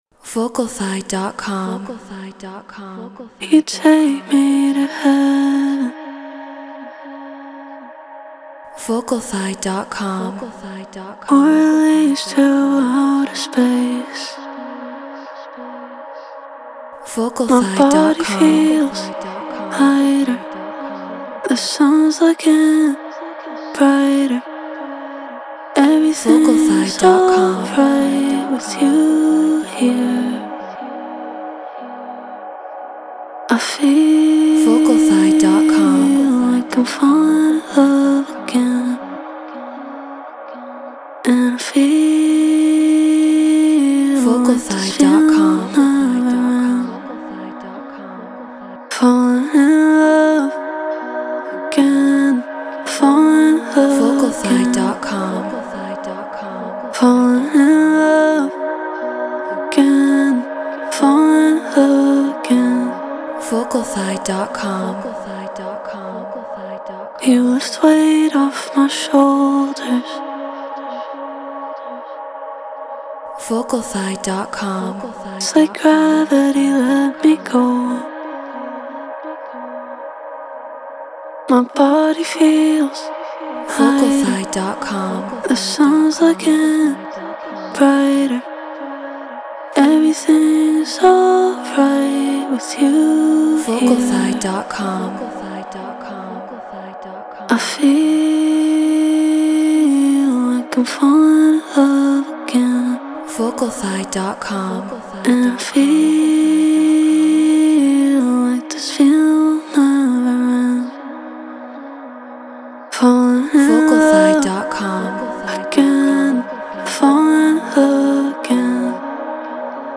Chill House 120 BPM Bmaj
Shure SM7B
Treated Room